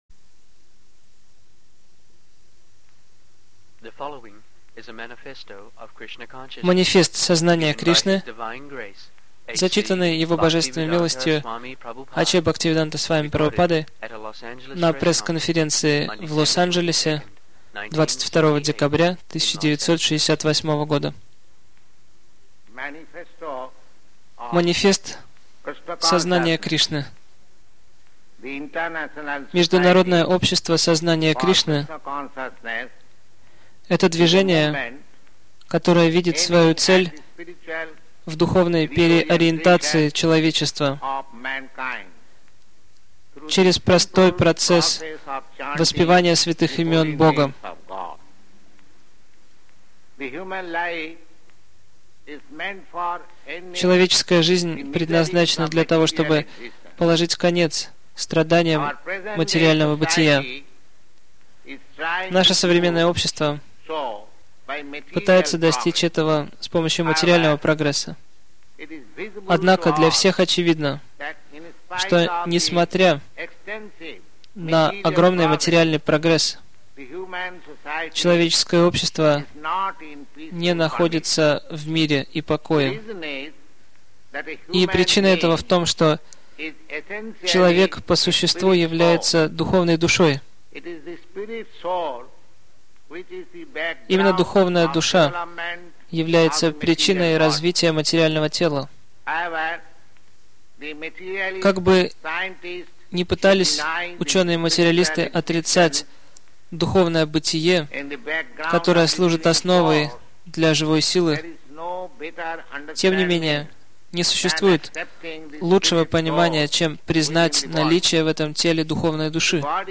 Манифест Сознания Кришны, зачитанный на пресс-конференции в Лос-Анджелесе